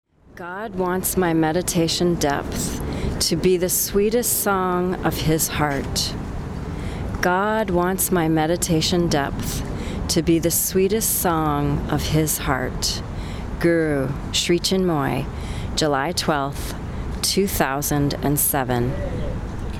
Poem of the Day